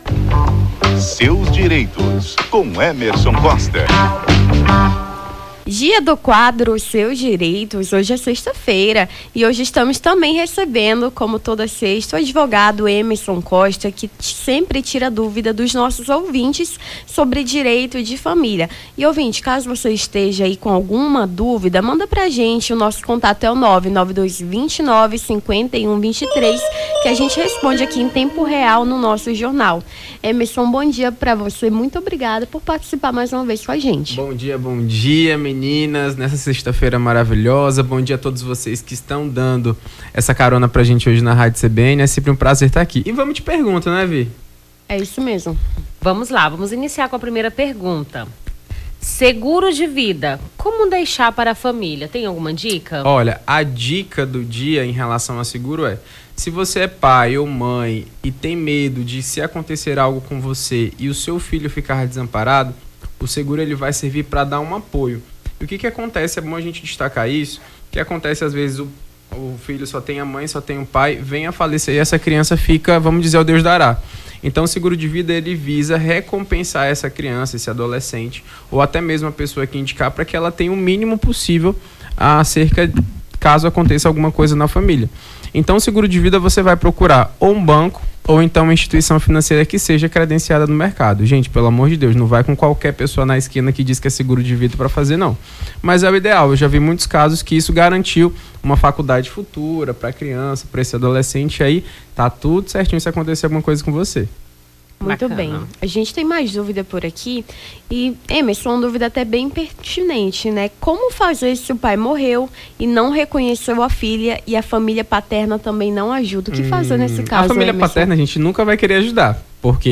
Seus direitos: advogado tira-dúvidas sobre direitos de família